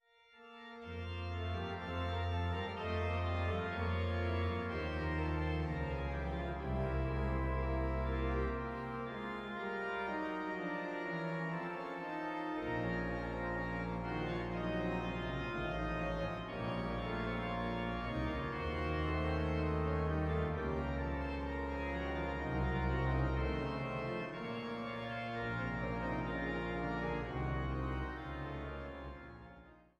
Woehl-Orgel in der Thomaskirche zu Leipzig